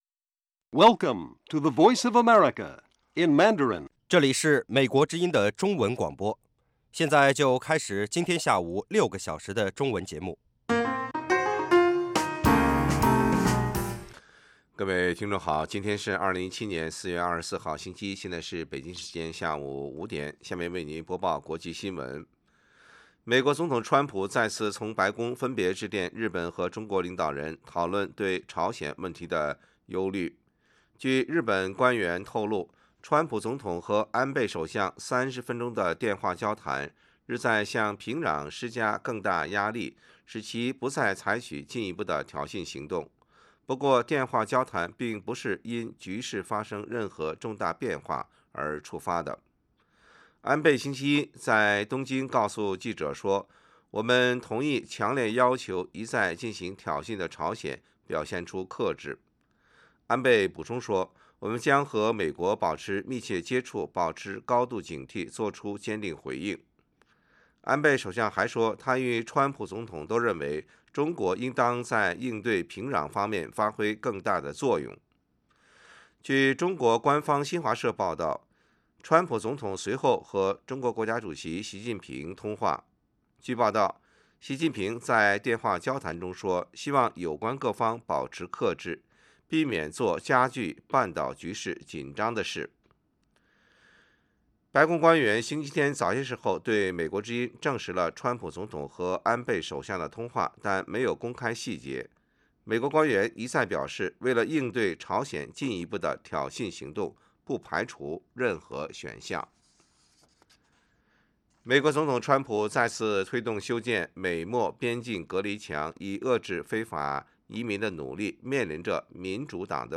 北京时间下午5-6点广播节目。广播内容包括国际新闻，新动态英语，以及《时事大家谈》(重播)